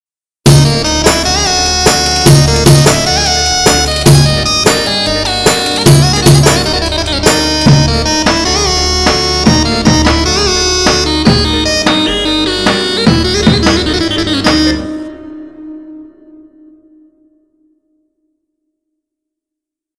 Bomba Bomba Bomba gibi pa500 roman setim sonunda paylaşıyorum
yeni yaptım bu ritimi